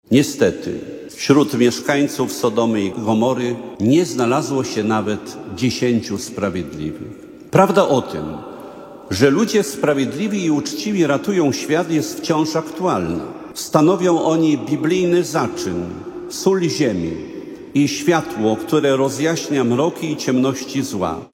O godzinie 9:00 w warszawskiej bazylice Świętego Krzyża na Krakowskim Przedmieściu odbyła się Msza święta kończąca centralne obchody setnej rocznicy powołania Policji Państwowej.
Mszy świętej przewodniczył i kazanie wygłosił bp Józef Guzdek, biskup polowy Wojska Polskiego. Odniósł się do czytanych dzisiaj fragmentów Pisma świętego mówiących o zniszczeniu Sodomy i Gomory.